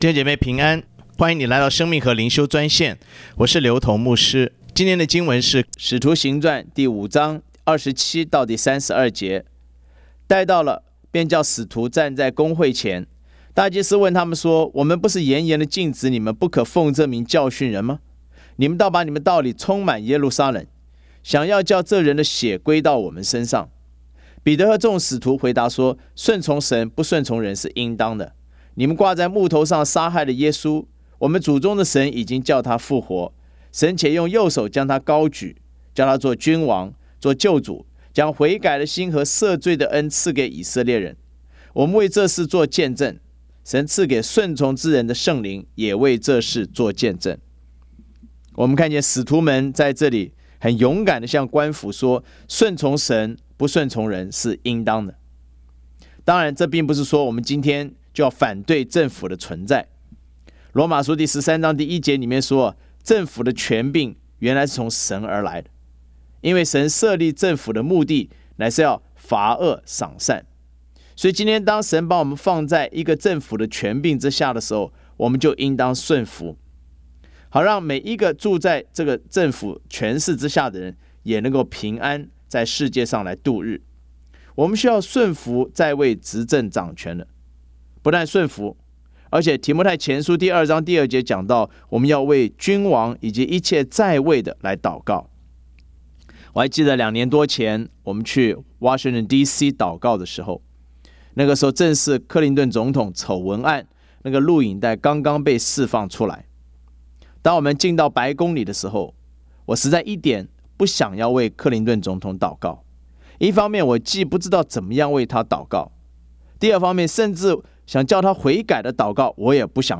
以生活化的口吻带领信徒逐章逐节读经